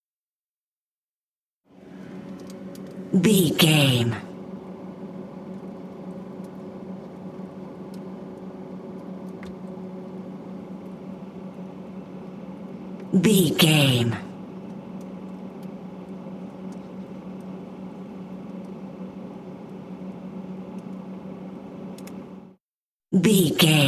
Refrigerator Int
Refrigerator Int 272
Sound Effects
house kitchen